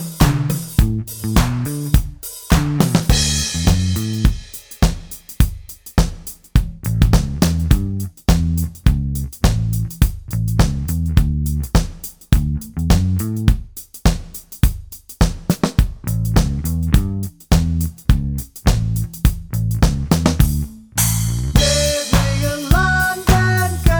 no Backing Vocals T.V. Themes 3:39 Buy £1.50